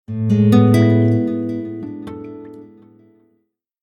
Звуки телефона Lenovo